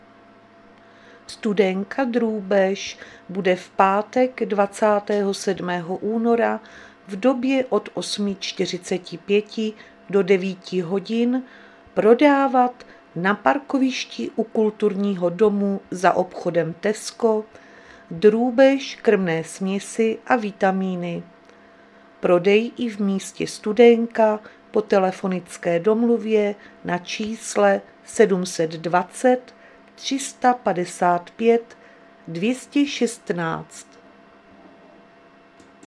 Záznam hlášení místního rozhlasu 26.2.2026